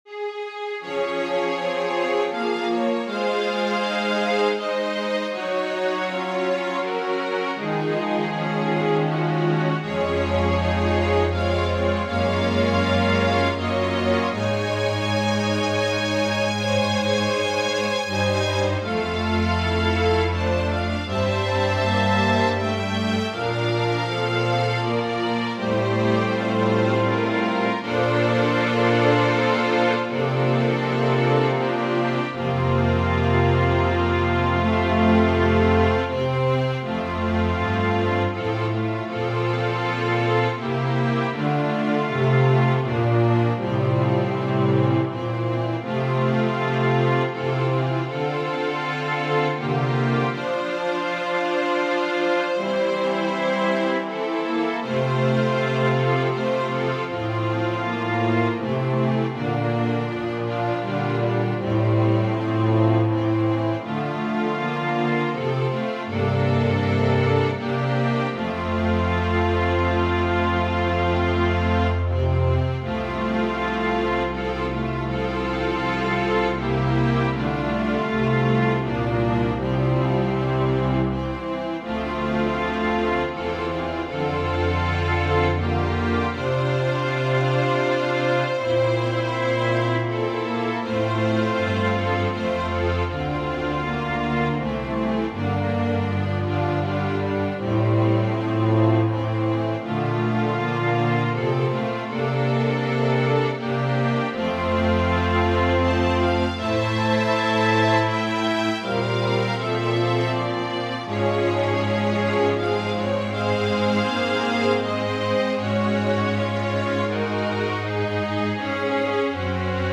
As an american folk song, I didn't think this piece needed to be really jazzed up as it deserves more reverence especially since it was always sang by a chaplain when one was about to pass or passed in the Intensive Care Unit.
Voicing/Instrumentation: Organ/Organ Accompaniment We also have other 38 arrangements of " Amazing Grace ".